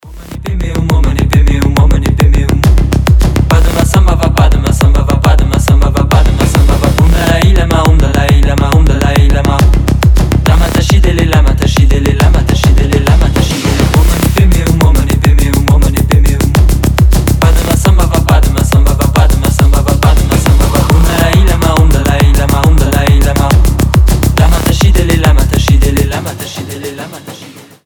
клубные
psy-trance , транс